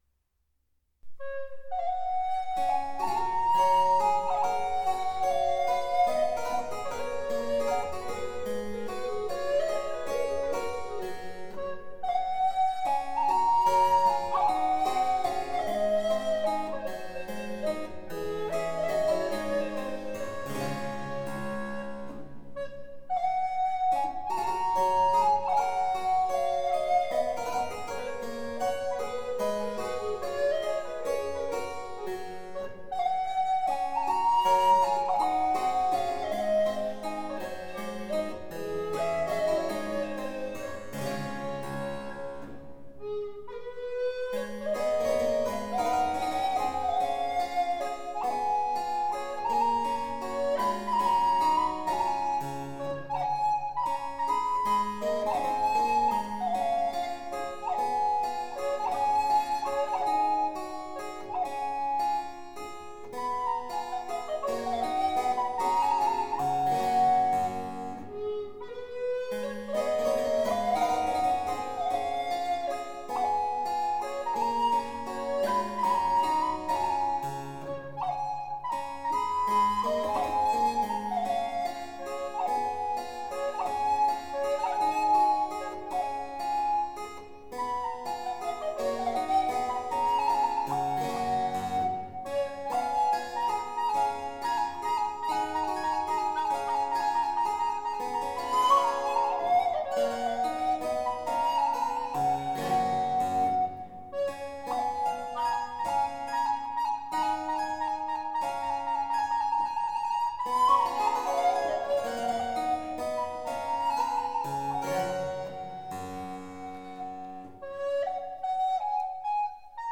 „Le Rossignol en Amour“ für Flöte und Cembalo